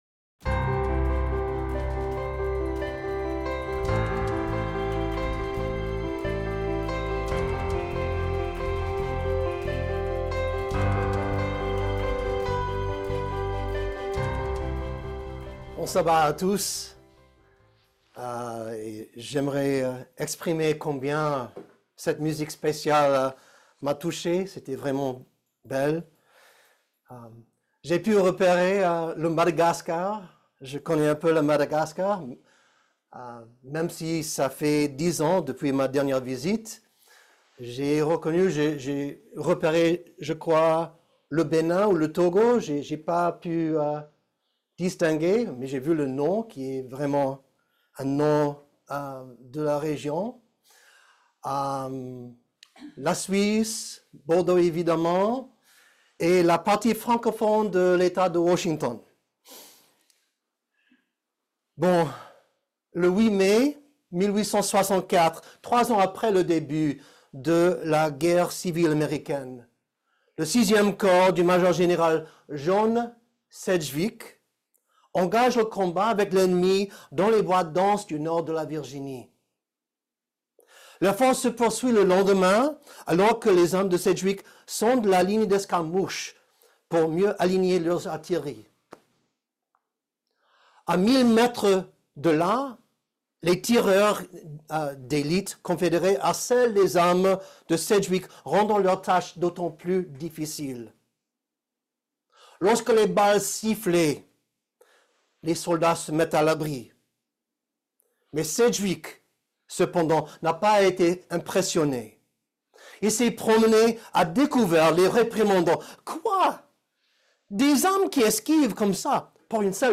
Ce sermon explore les profondeurs de l’humilité à travers des exemples bibliques, historiques et personnels. Il souligne la nécessité d’une évaluation honnête de soi, la reconnaissance de Dieu comme source de tout don, et l’importance de considérer les autres comme supérieurs à nous-mêmes.